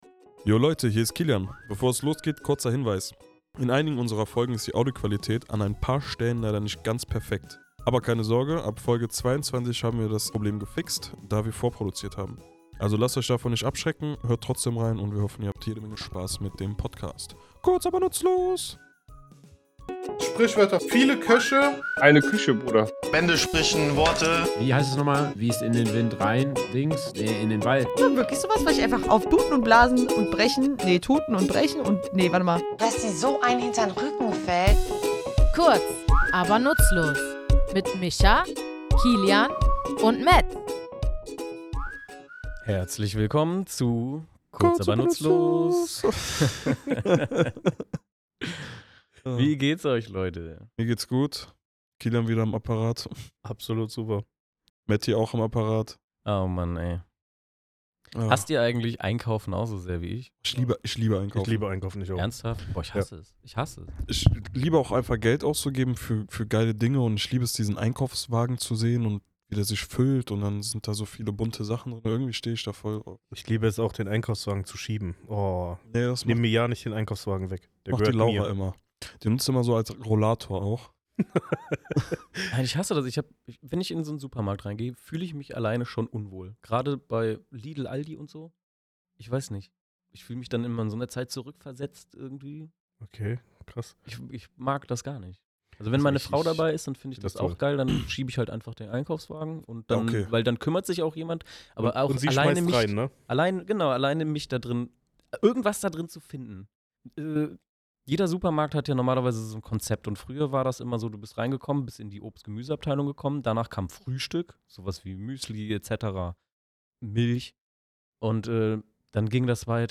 Was macht diesen Begriff so besonders, und warum heißt das elegante Insekt gerade so? Wir, drei tätowierende Sprachnerds, tauchen in unserem Tattoostudio in die Herkunft, Bedeutung und den klangvollen Zauber dieses Wortes ein.